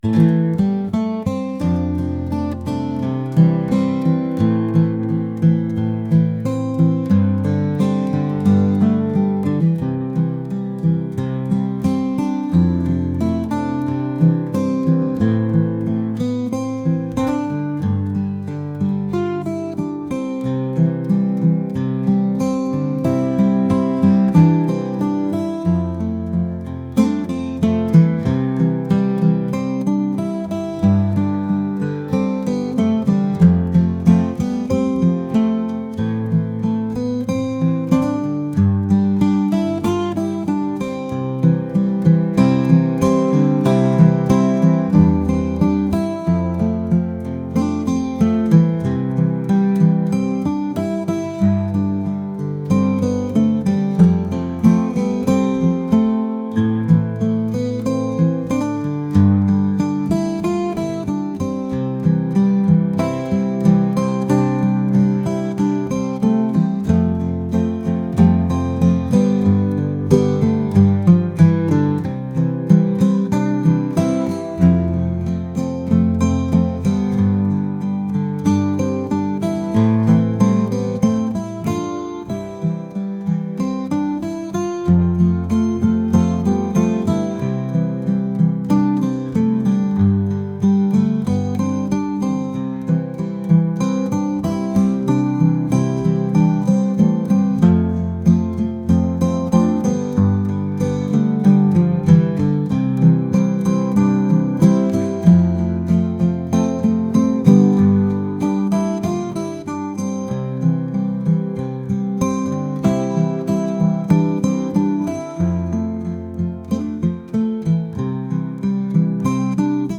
acoustic | folk | laid-back